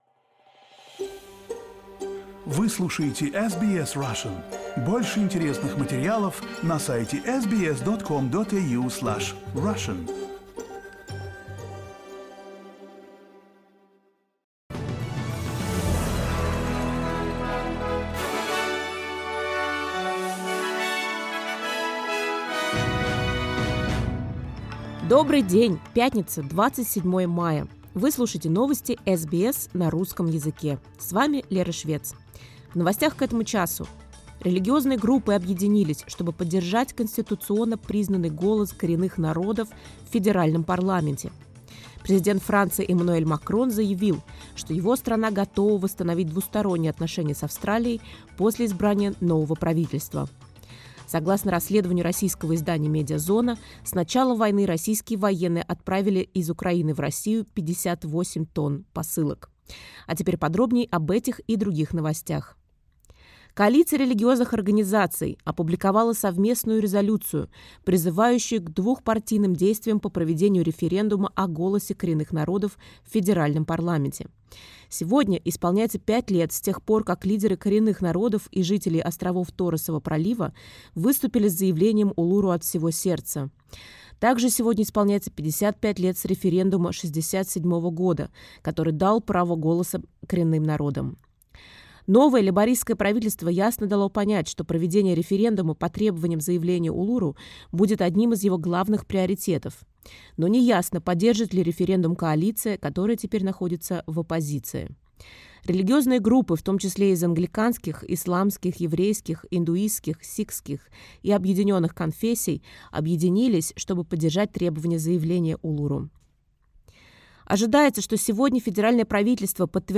SBS news in Russian — 27.05.22